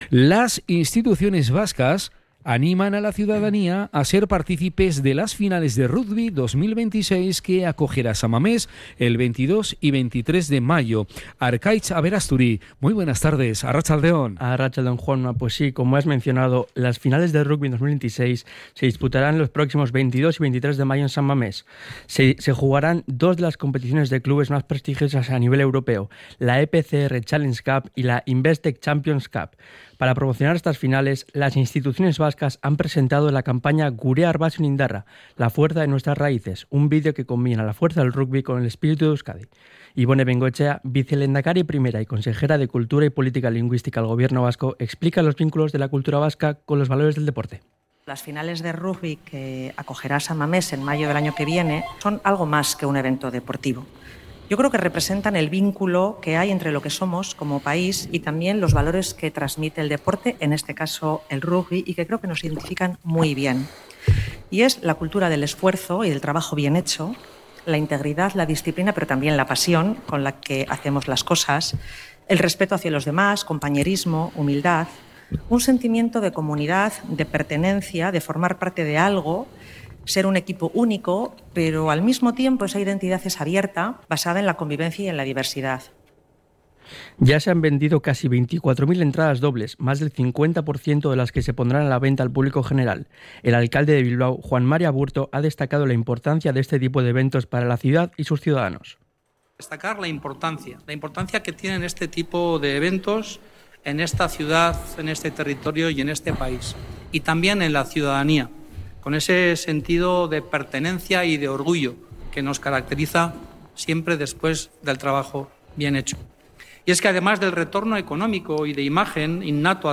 CRONICA-RUGBY.mp3